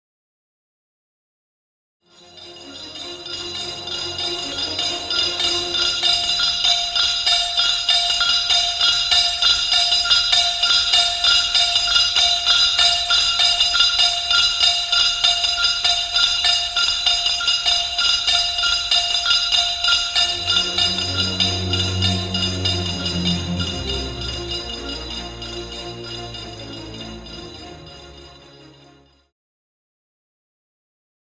Wagner  uses 18 tuned anvils to create the sound of the dwarfish smiths of Nibelheim slaving under Alberich’s tyrannical yoke.
Anvils
anvils.mp3